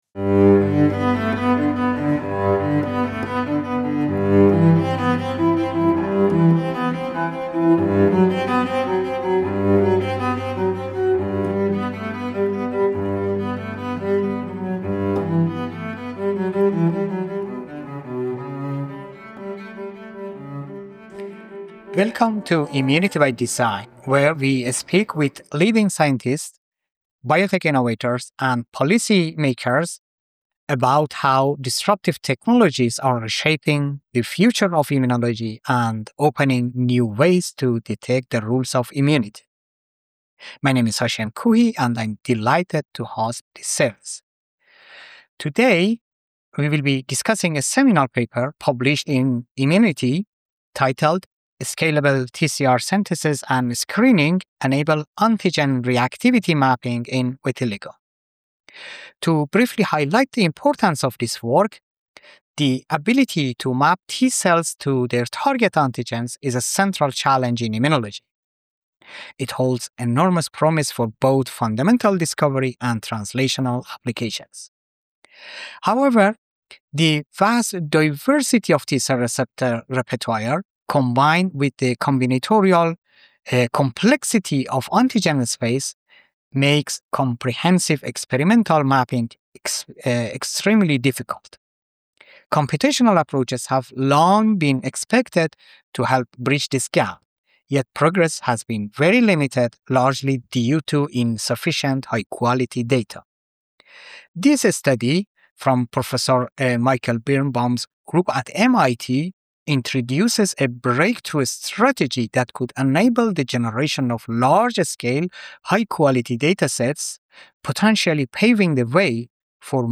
The conversation explores the technological hurdles of many-to-many TCR antigen screening, the importance of accessible experimental tools for building community-scale datasets, and how such data could accelerate the development of computational models that predict immune recognition.